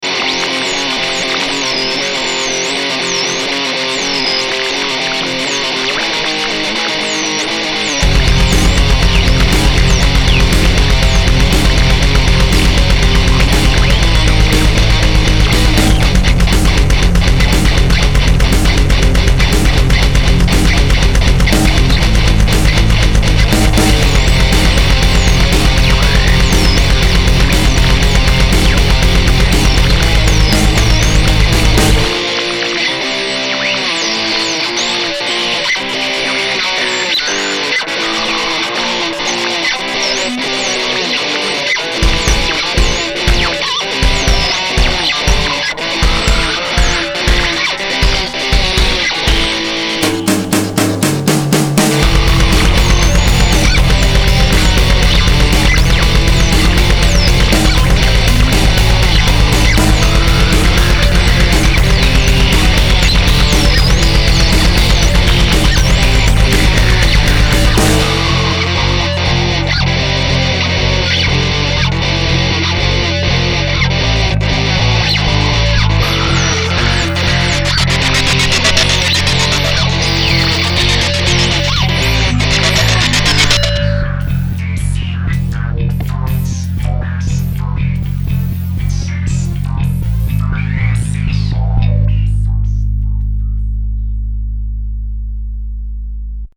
Записана дома.